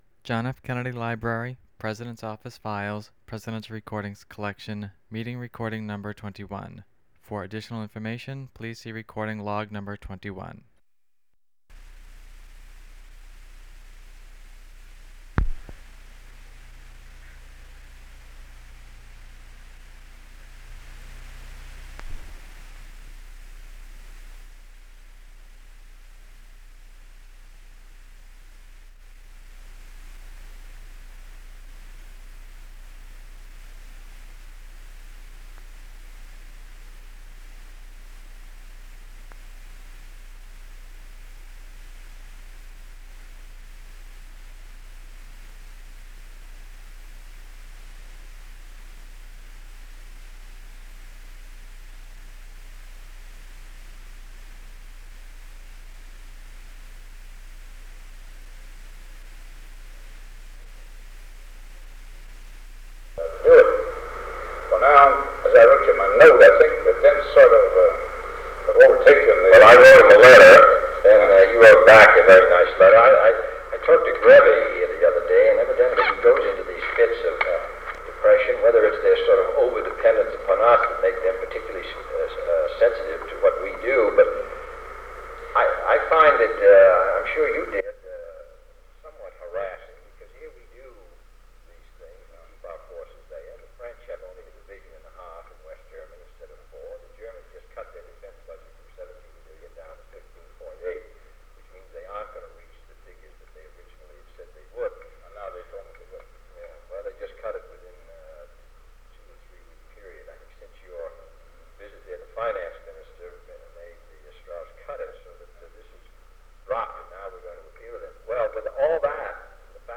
Secret White House Tapes | John F. Kennedy Presidency Meeting with Dwight Eisenhower Rewind 10 seconds Play/Pause Fast-forward 10 seconds 0:00 Download audio Previous Meetings: Tape 121/A57.